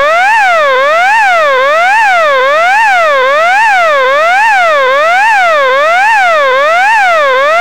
Provides up to 85 dB at 5 feet.
102/108 Rapid Siren Sound - 167.9K
rapid_siren.wav